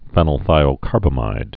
(fĕnəl-thīō-kärbə-mīd, -kär-bămīd, fēnəl-)